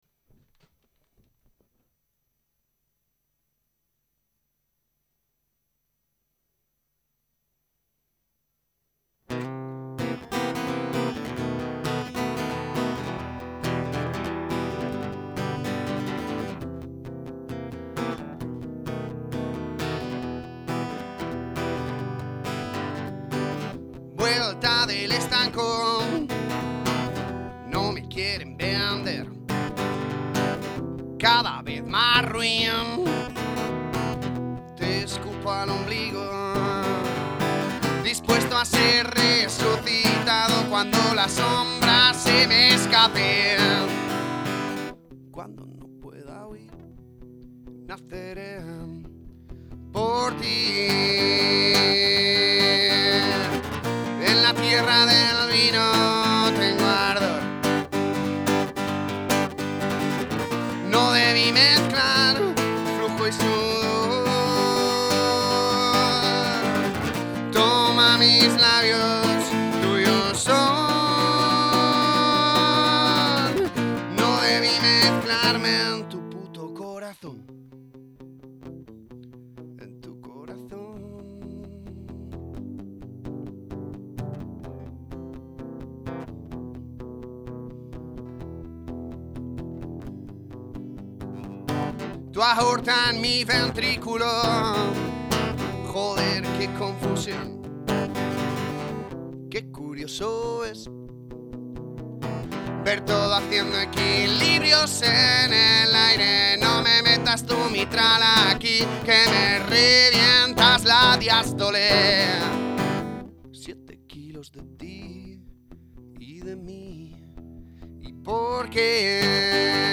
(do como si 7º, si 7º, mi menor y sol mayor con bajo en fa,
estribillo la# mayor, la menor y sol mayor, excepto la frase de «tuyos son» que es la# mayor, la menor y si mayor)